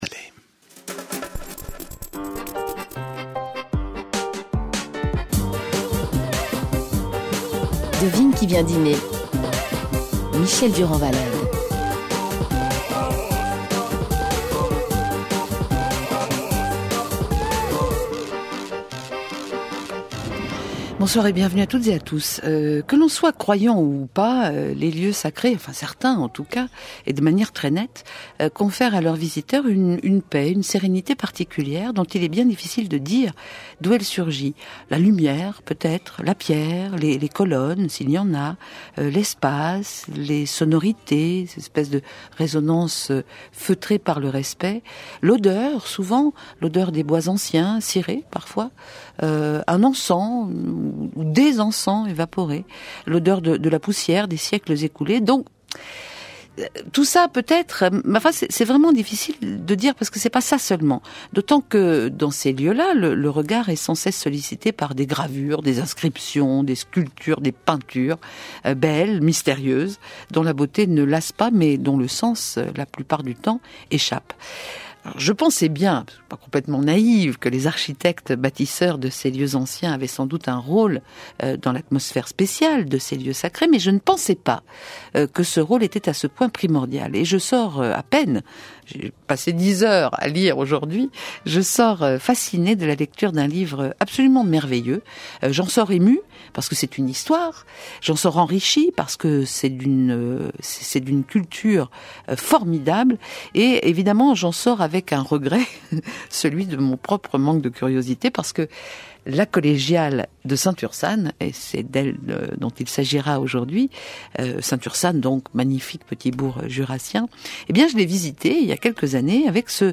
L'interview radio